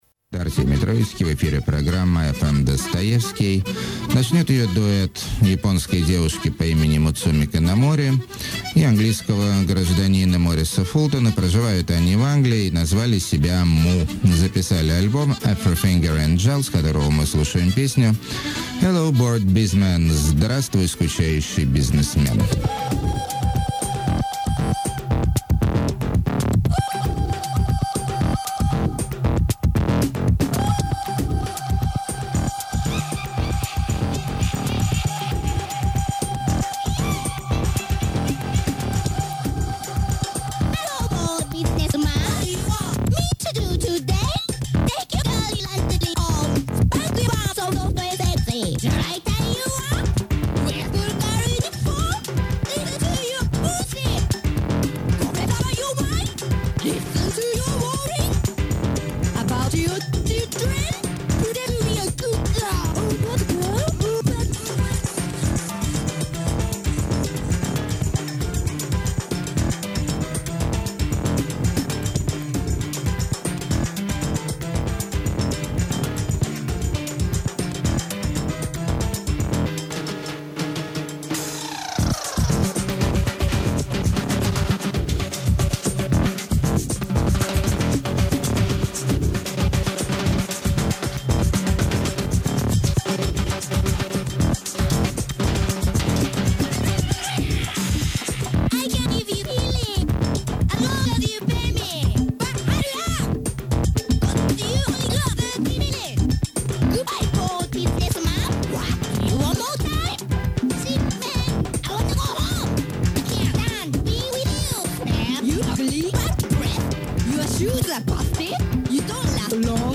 euro-japanese electro satire
delicate white chanson-hop
epic psycho-pop
alcoholic alterno-country
powerful electric afro
subtle feminine indie rock
post-rock drone maximalism
balkan ethno-fusion